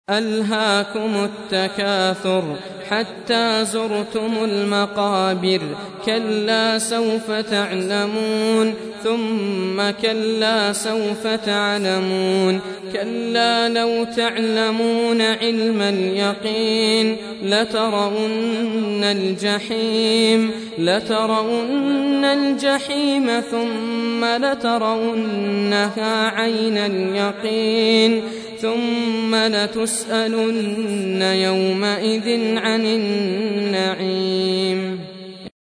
102. Surah At-Tak�thur سورة التكاثر Audio Quran Tarteel Recitation
Surah Sequence تتابع السورة Download Surah حمّل السورة Reciting Murattalah Audio for 102.